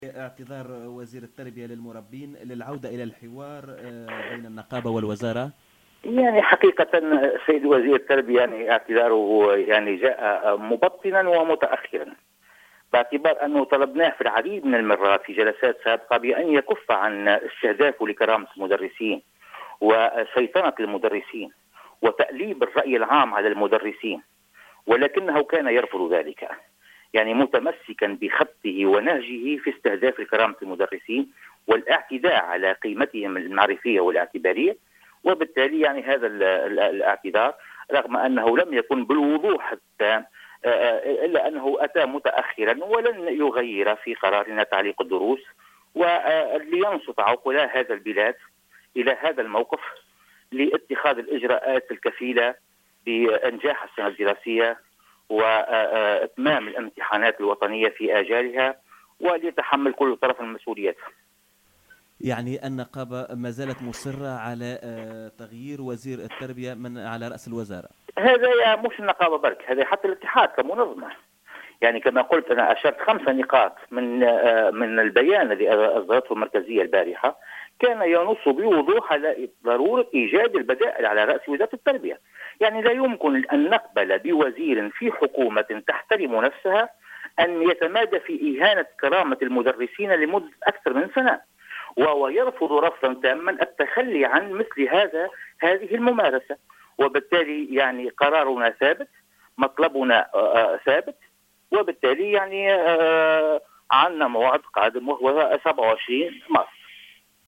وأوضح في تصريح لـ "الجوهرة أف أم" أن النقابة طلبت من الوزير الكف عن استهداف المدرسين وتأليب الرأي العام ضدّهم لكنه استمرّ في ممارساته بإهانة كرامة المدرسين والاعتداء على قيمتهم المعرفية والاعتبارية، بحسب تعبيره.